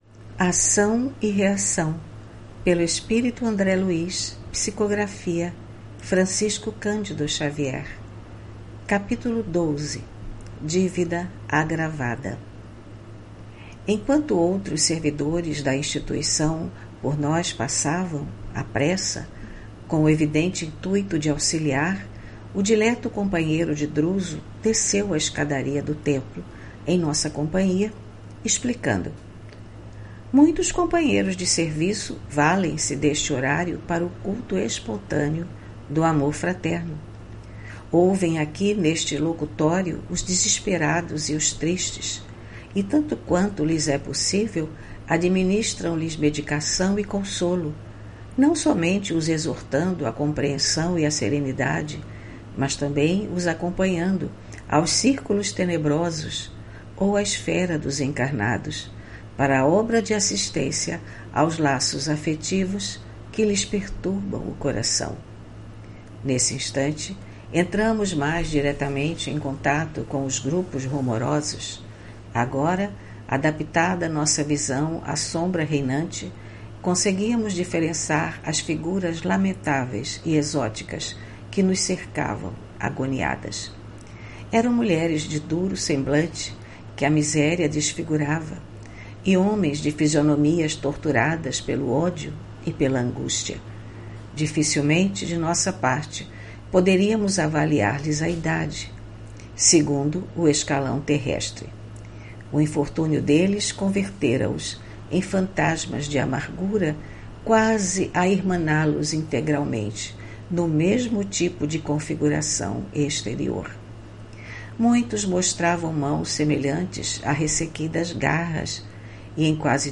Leitura do livro: Ação e reação, autoria do espírito André Luiz, psicografia de Francisco Candido Xavier.